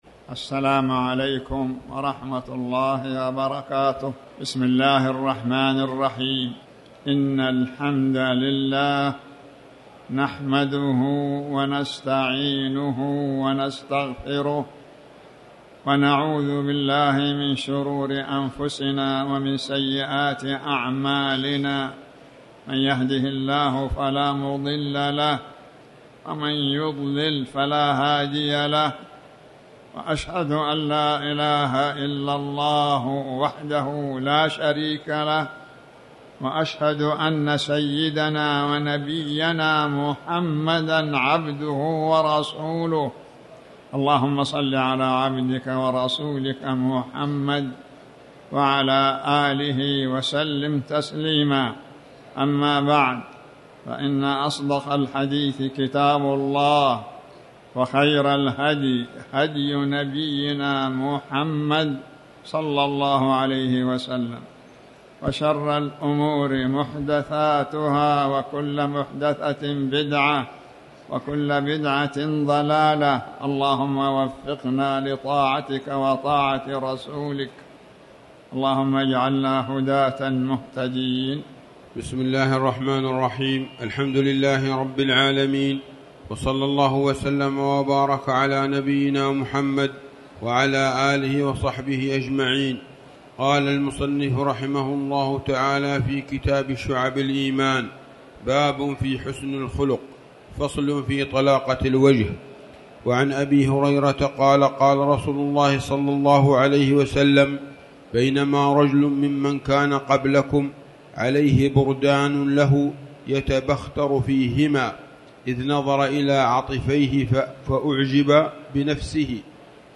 تاريخ النشر ١١ ذو القعدة ١٤٤٠ هـ المكان: المسجد الحرام الشيخ